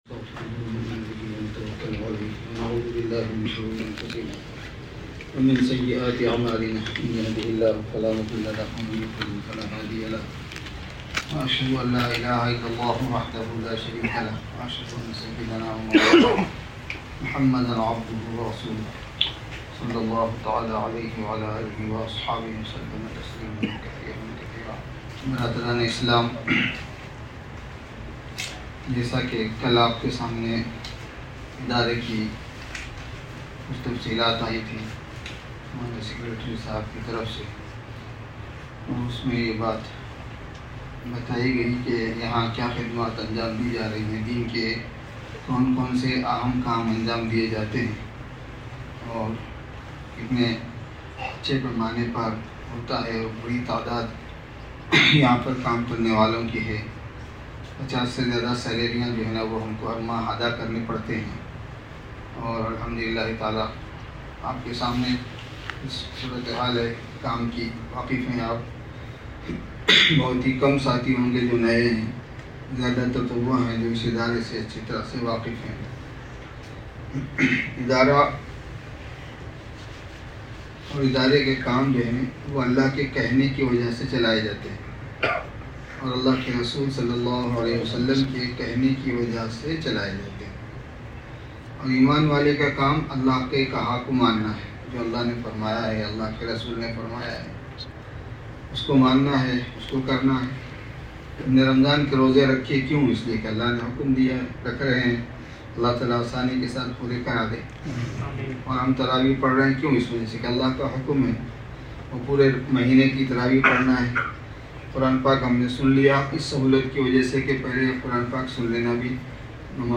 (Jum'ah Bayan)